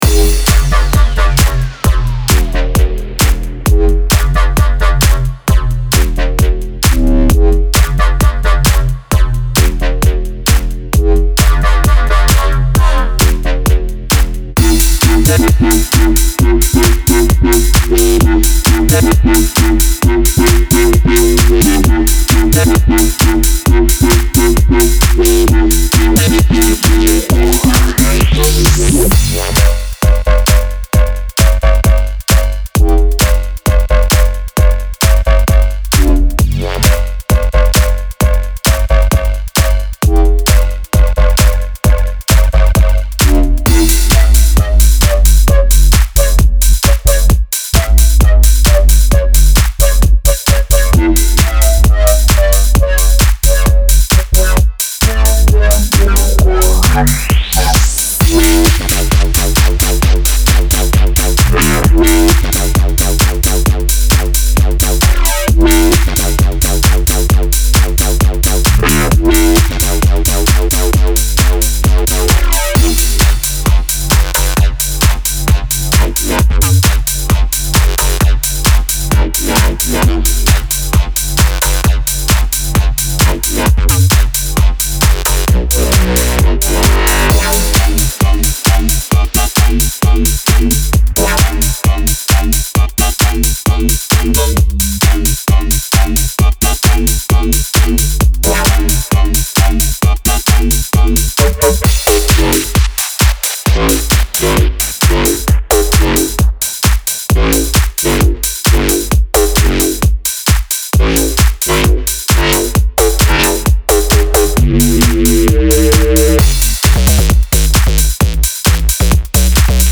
收尾包中有13个低音循环，这些循环在预览中提供，展示了这些预设可以做什么。
这些预设在设计时就考虑了英国低音之家/车库，但在其他流派中却很常见，例如DnB，Jump Up，Break，Dubstep和其他风格的House。
样本包中未包含演示中的鼓声和FX声音。